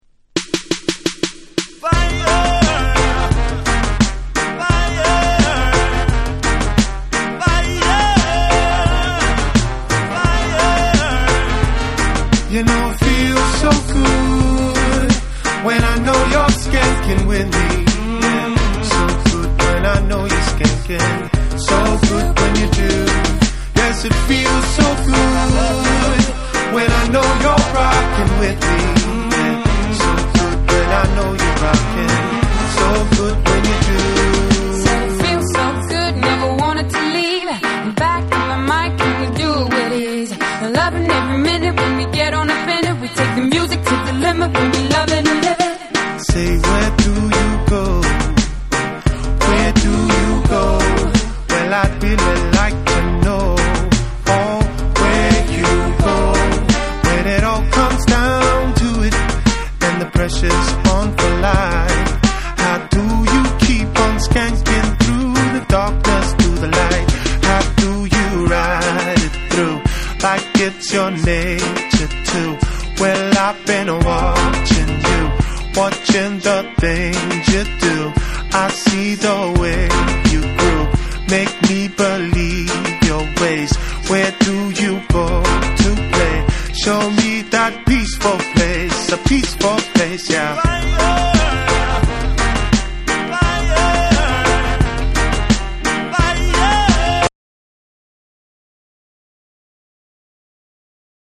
強烈なダンスホール・ビートにリミックスされた2。
BREAKBEATS / REGGAE & DUB